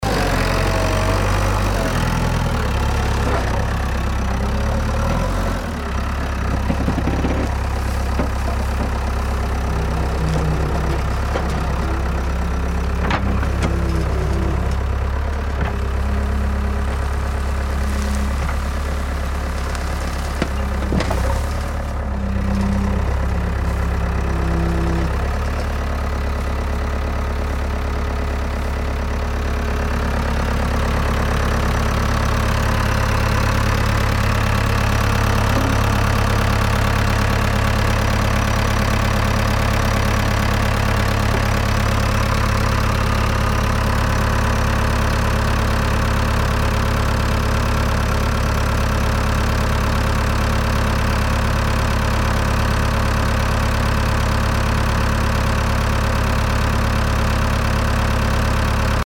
This ambience captures the powerful, mechanical atmosphere of an active construction site. The sound includes realistic JCB excavator movements, metal bucket impacts, digging, scraping, soil lifting, hydraulic arm pressure, and engine idling.
Layered with additional construction elements such as hammering, distant drilling, stone breaking, workers’ movement, and occasional tool clanks, this ambience delivers a fully immersive industrial environment. The sound is dynamic—JCB machines move closer and farther, creating natural depth and motion.
Industrial Machinery Ambience
Construction / Heavy Vehicle
Loud, Industrial, Mechanical